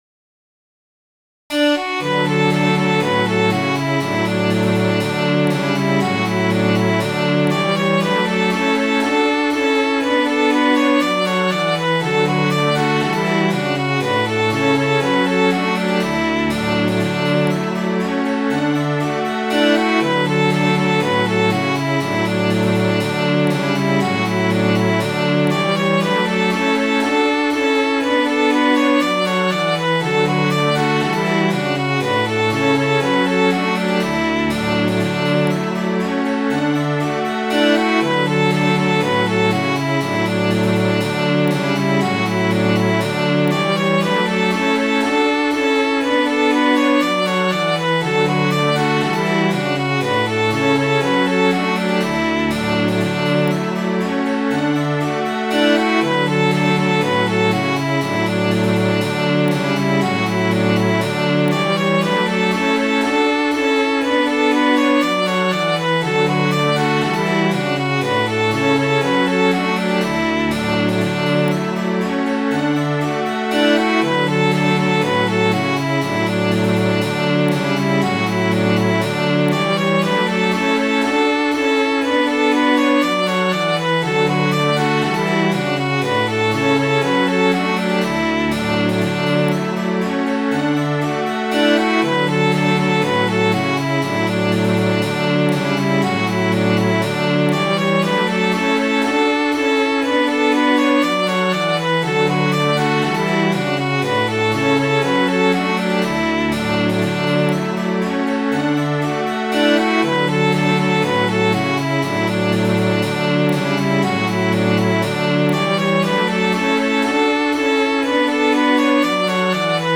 Midi File, Lyrics and Information to Rolling in the Dew